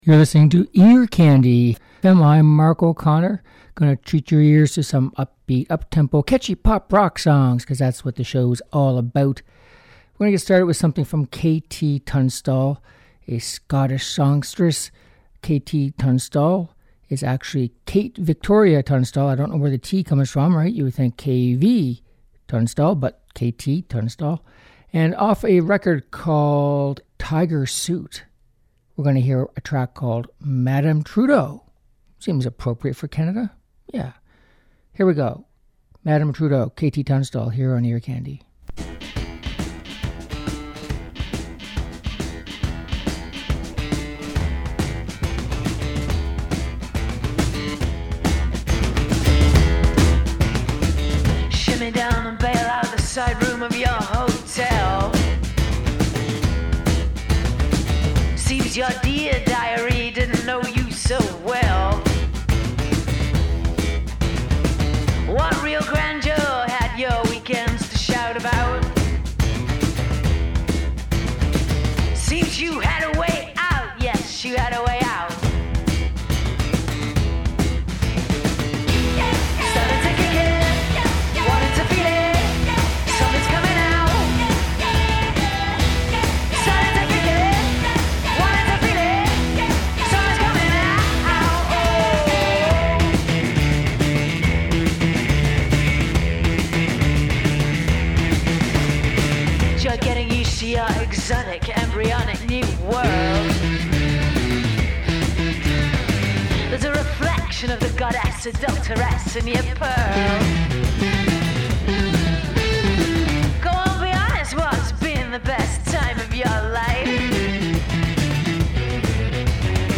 Upbeat Pop and Rock Songs